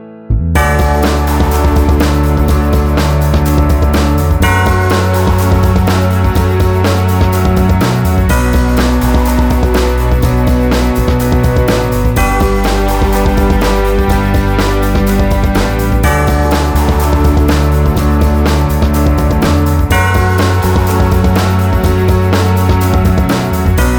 no BV Pop (2010s) 3:25 Buy £1.50